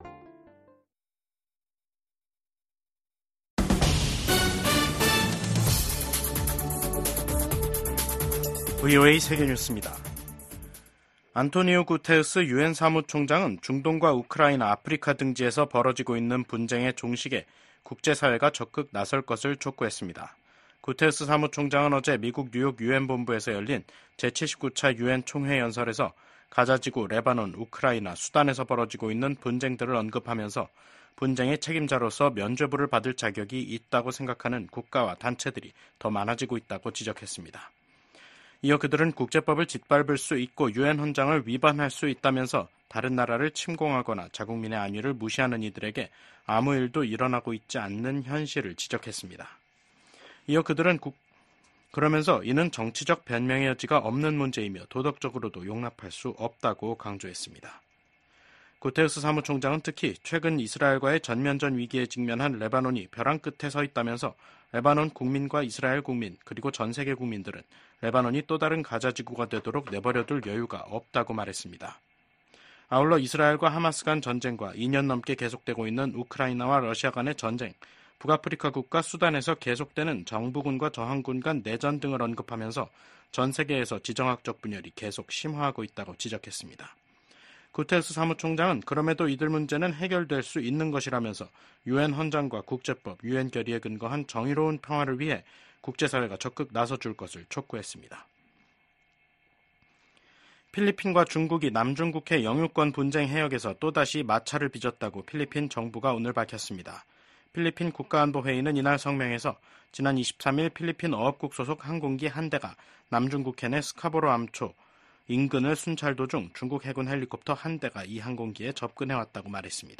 VOA 한국어 간판 뉴스 프로그램 '뉴스 투데이', 2024년 9월 25일 2부 방송입니다. 조 바이든 미국 대통령이 임기 마지막 유엔총회 연설에서 각국이 단합해 전 세계가 직면한 어려움들을 이겨내야 한다고 밝혔습니다. 토니 블링컨 미국 국무장관은 심화되는 북러 군사협력을 강하게 규탄하며 국제사회의 강력한 대응의 필요성을 역설했습니다.